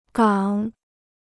港 (gǎng): harbor; port.